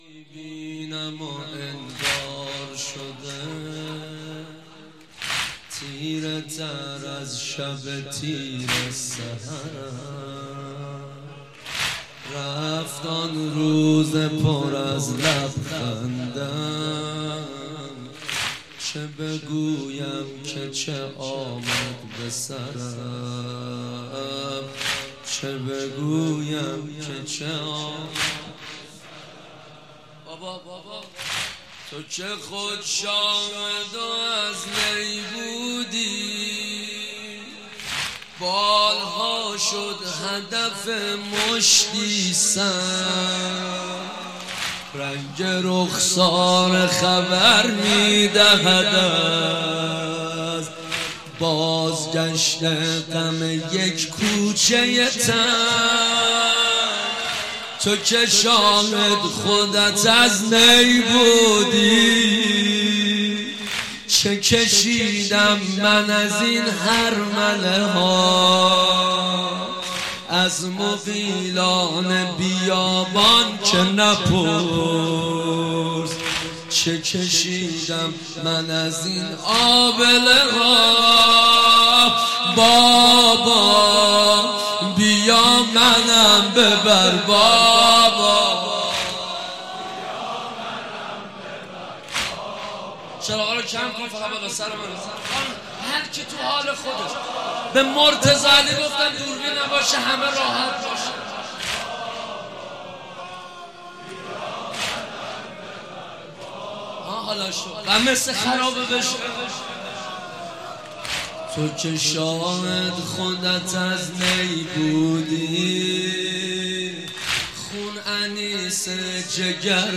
مداح
مناسبت : شب سوم محرم